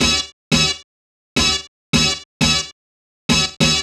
cch_synth_loop_funker_125_E.wav